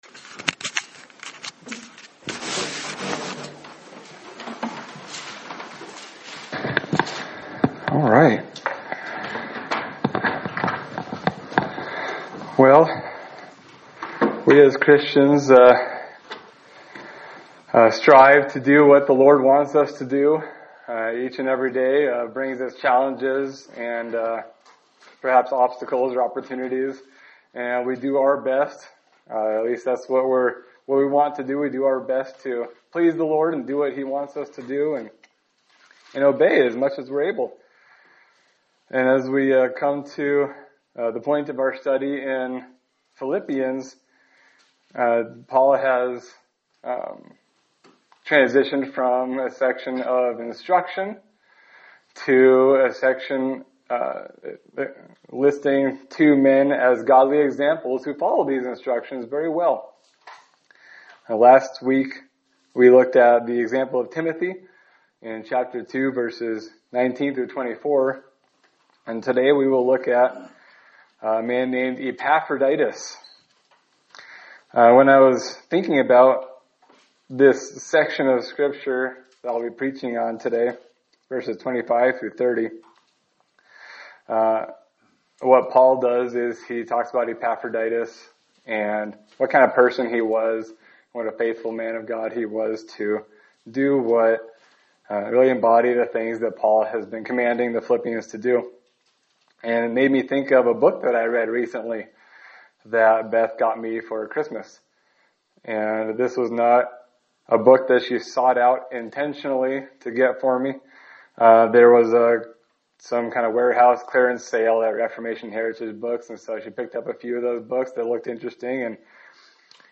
Sermon for June 22, 2025
Service Type: Sunday Service